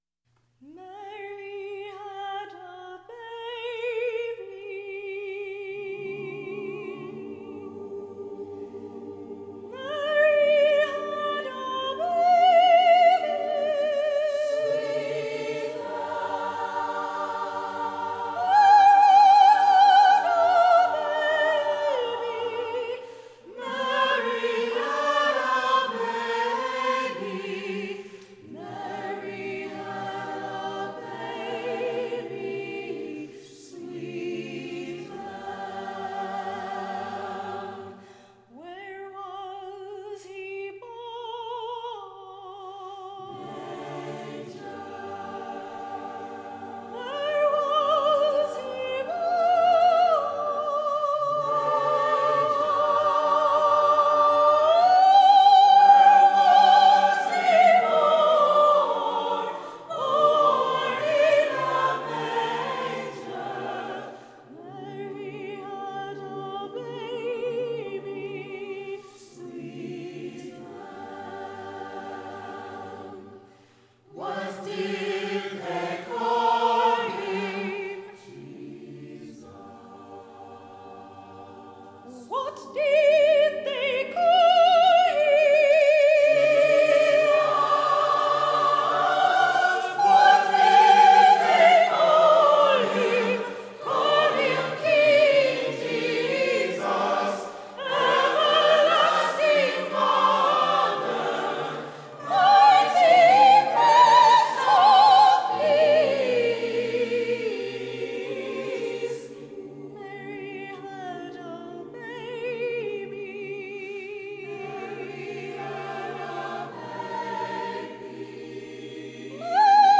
Fayetteville State University Chamber Singers “A Christmas Celebration” 1999
choral, choir, performance